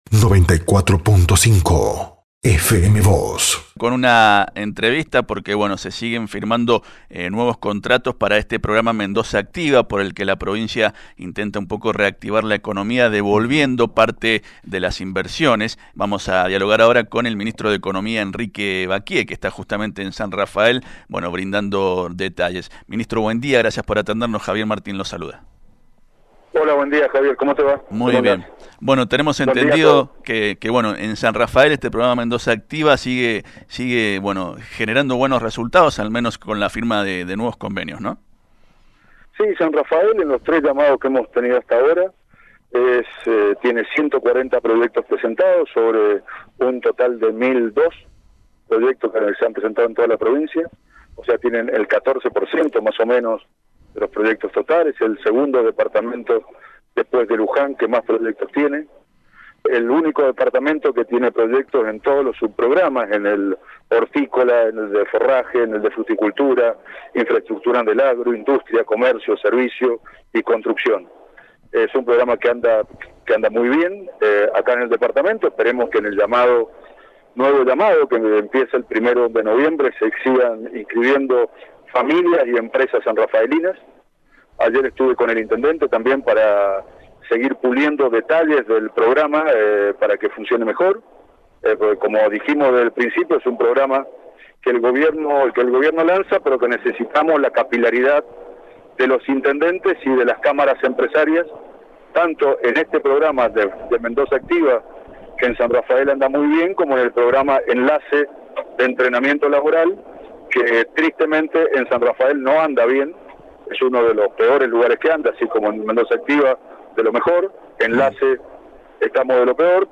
A través de FM Vos (94.5) y Diario San Rafael, el ministro de Economía de Mendoza, Enrique Vaquié, se refirió